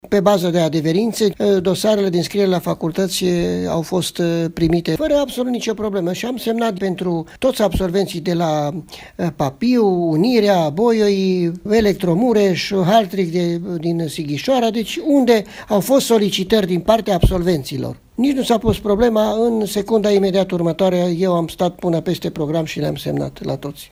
Absolvenții de liceu au putut să se înscrie la facultate în baza unei adeverințe eliberate de unitatea pe care au absolvit-o, a declarat pentru Radio Tg.Mureş, inspectorul școlar general al județului Mureș, Ioan Macarie: